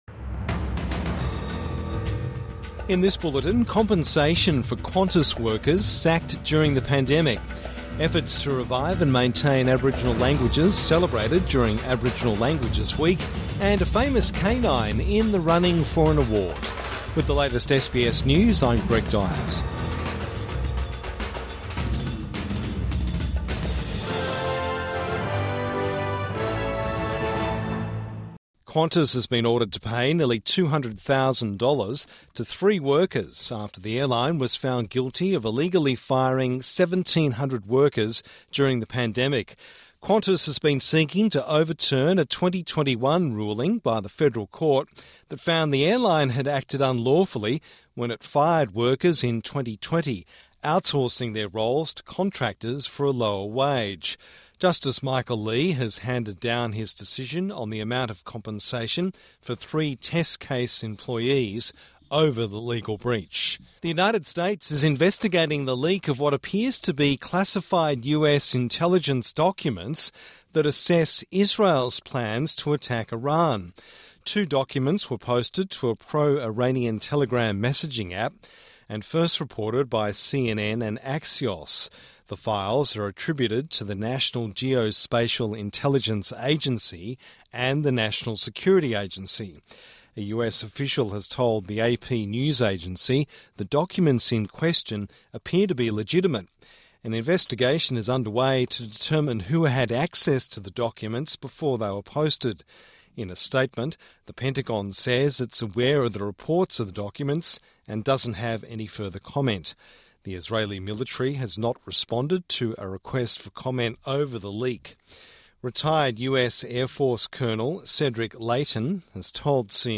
Midday News Bulletin 21 October 2024